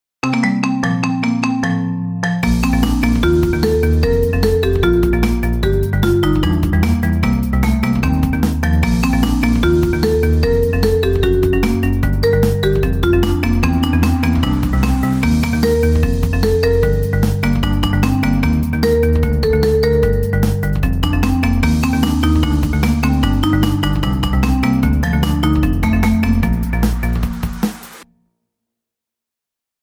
ジャンル iphone 着信音